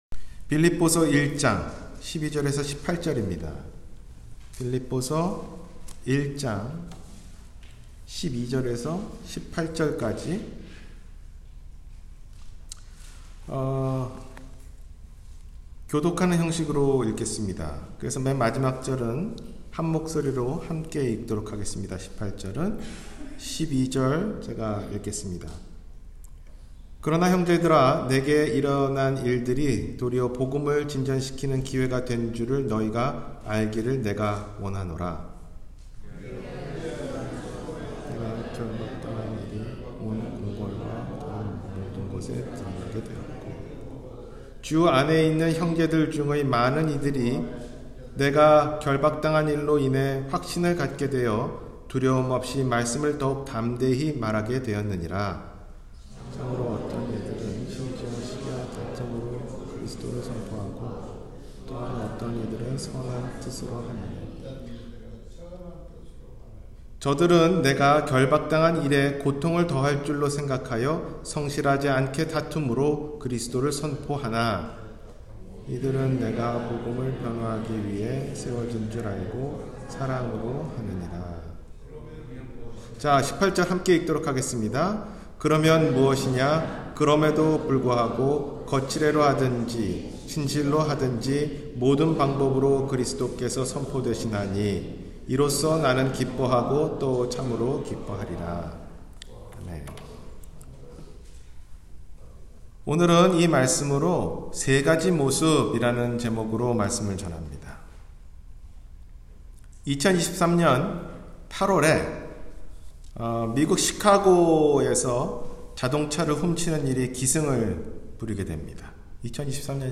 세가지 모습 – 주일설교